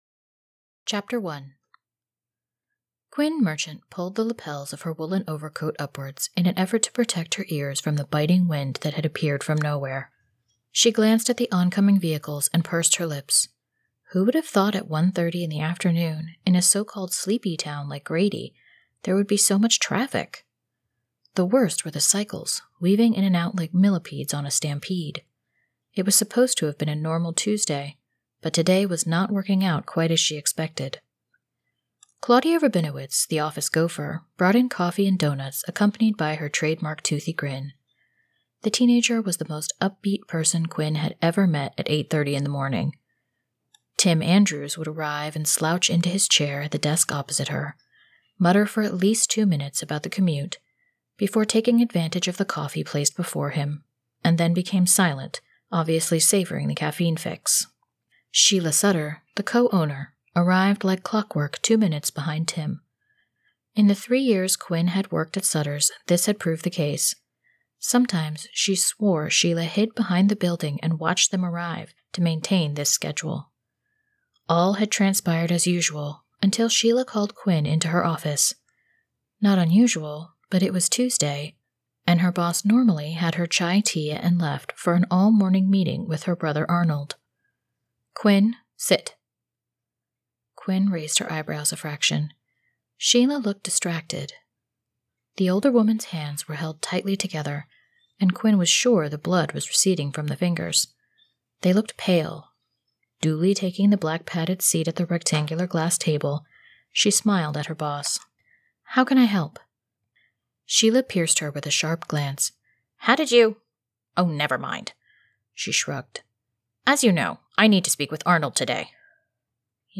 At Last by JM Dragon [Audiobook]